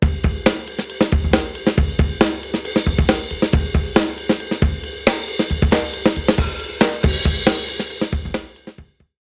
(ドラム部分)